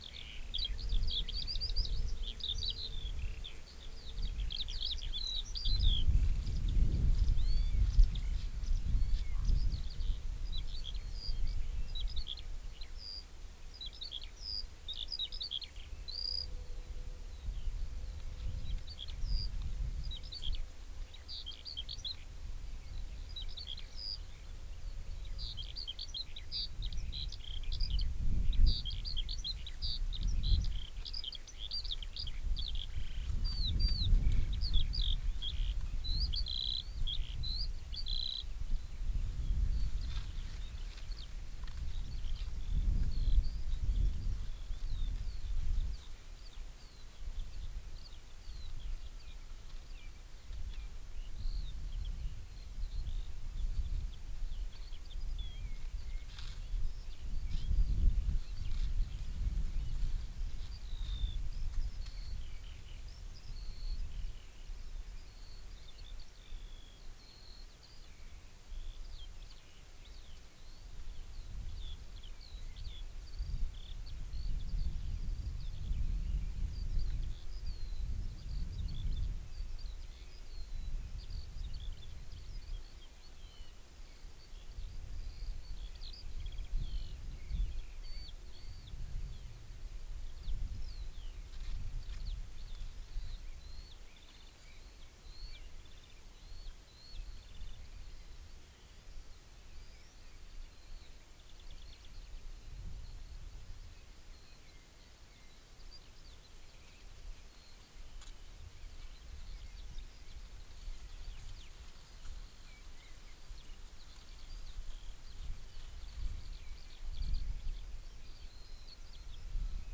Columba palumbus
Alauda arvensis
Turdus philomelos
Turdus merula
Coccothraustes coccothraustes
Corvus corax
Apus apus